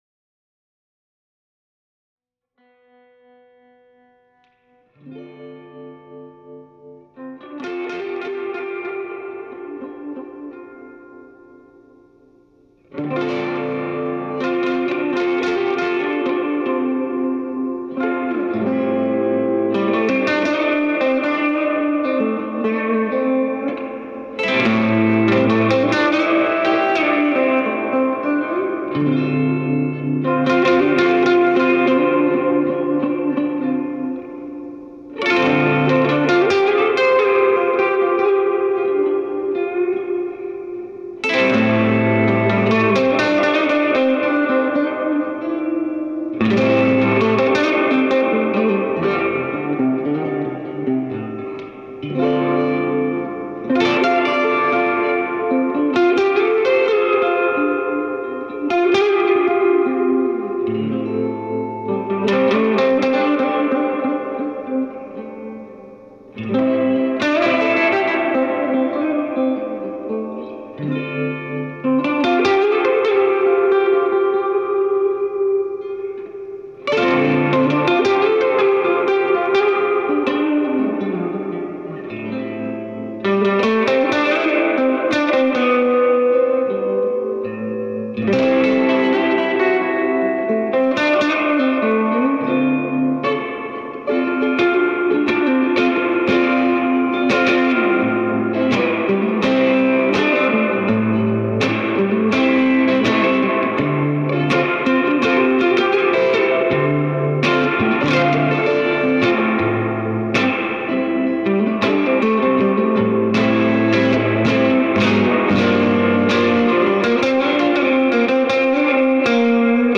The cab was mic'd with one SM57 on each speaker.
This cut featured the ST-Stereo's big reverb w/ hint of trem.
swart_st-stereo-blues_redux.mp3